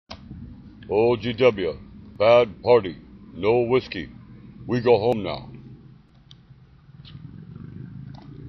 Indian Speaking To G W Mclintock
The Indian is more over the top.